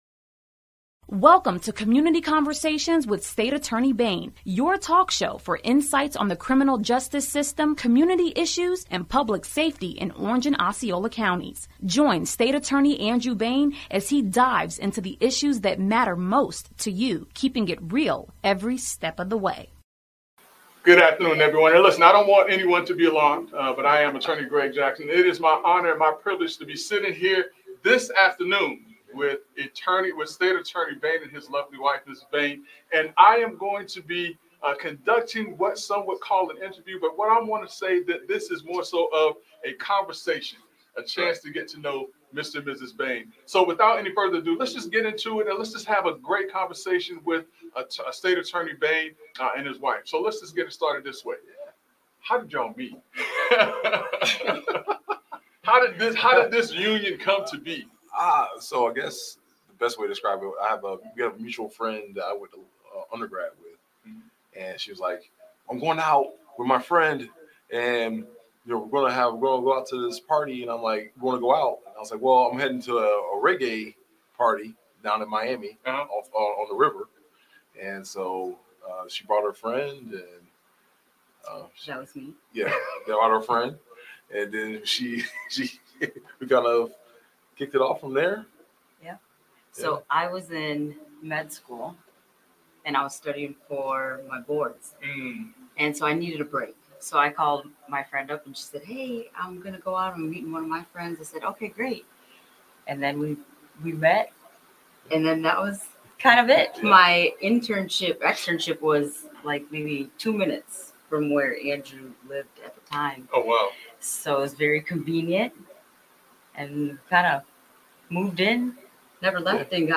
Community Conversations with State Attorney Bain Fireside Chat